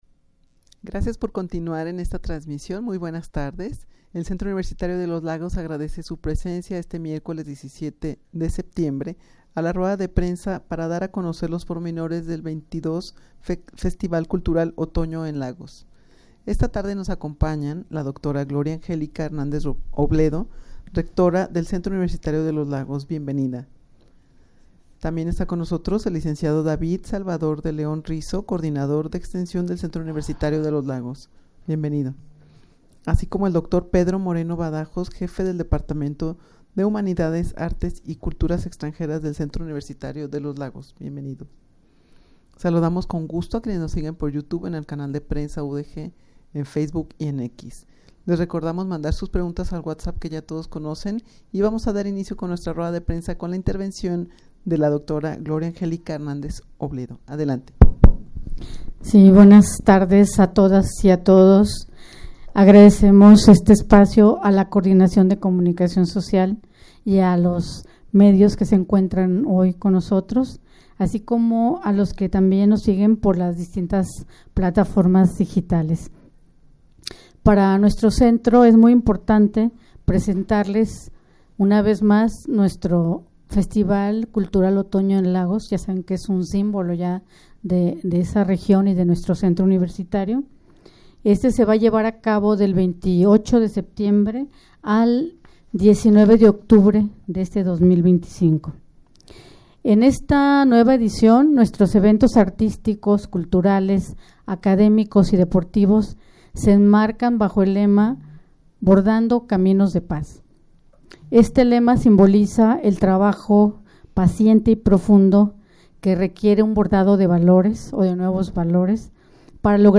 rueda-de-prensa-para-dar-a-conocer-los-pormenores-del-xxii-festival-cultural-otono-en-lagos.mp3